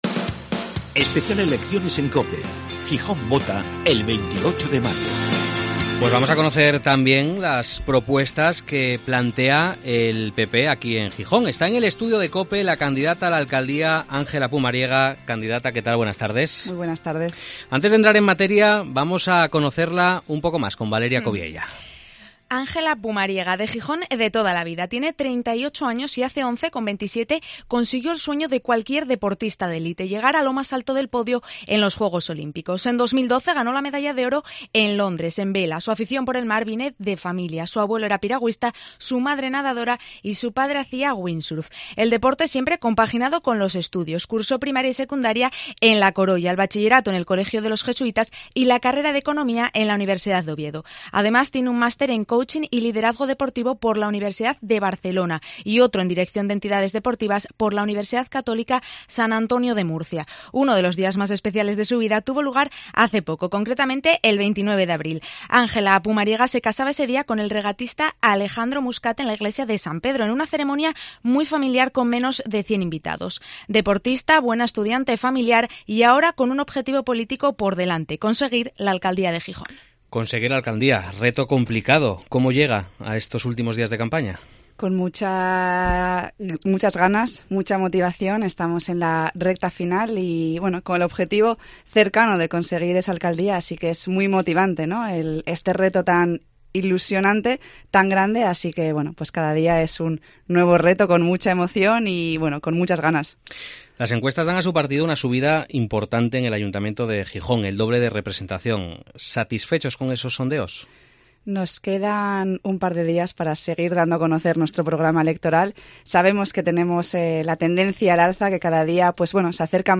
En COPE hablamos con los candidatos, pero también escuchamos a los ciudadanos, sus inquietudes y peticiones. Escucha el Especial Elecciones Municipales en COPE Gijón de este martes, con la entrevista a la candidata del Partido Popular a la Alcaldía de Gijón, Ángela Pumariega.